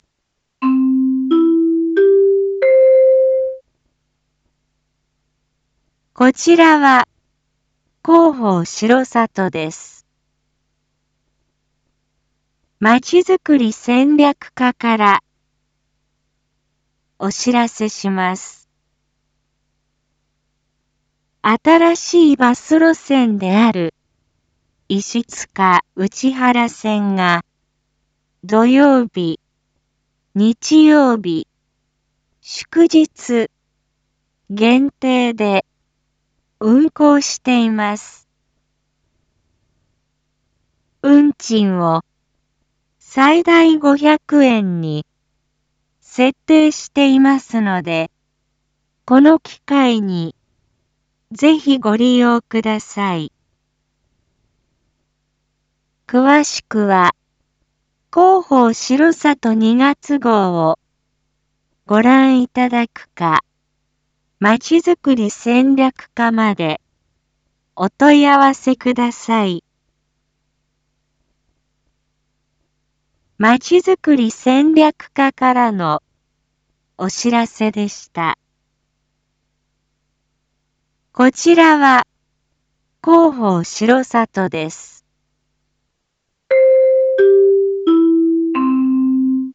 Back Home 一般放送情報 音声放送 再生 一般放送情報 登録日時：2024-04-19 19:01:32 タイトル：石塚・内原線の運行開始について④ インフォメーション：こちらは広報しろさとです。